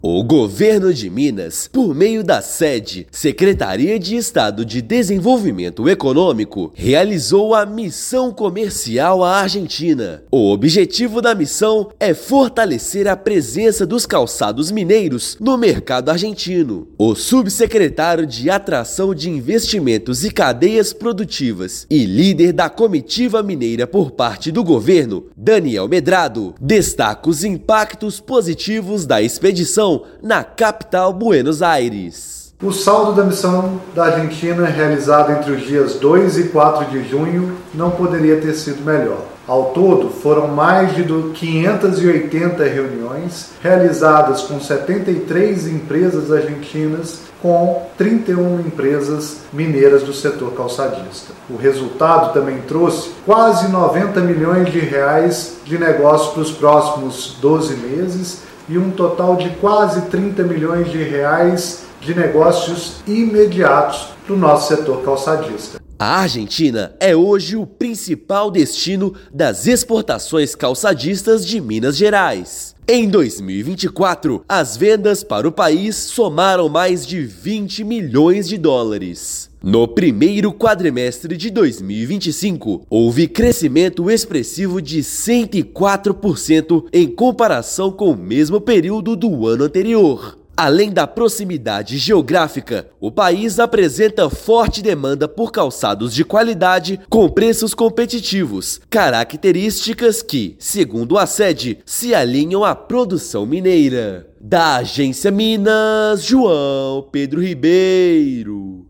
Trinta e uma empresas mineiras participaram de cerca de 600 reuniões com compradores argentino; expectativa é de mais R$ 90 milhões até o próximo ano. Ouça matéria de rádio.